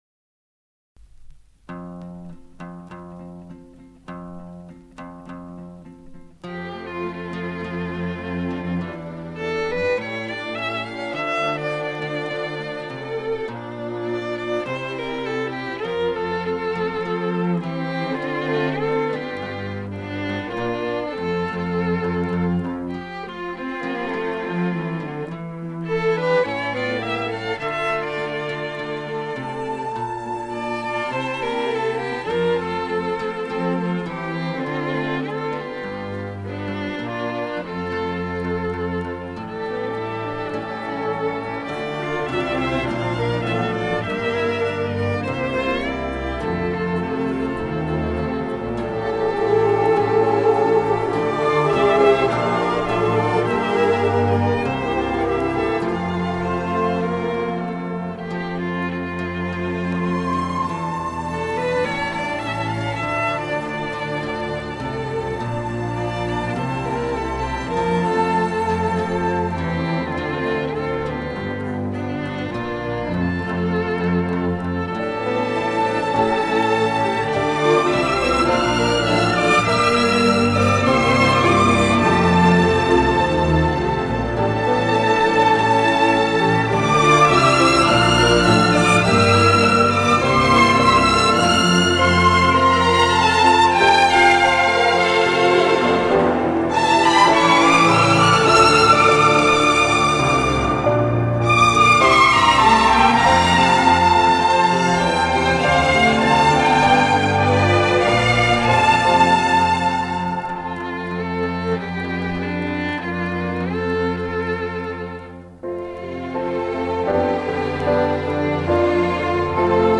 Genre:Pop, Rock
Style:EasyListening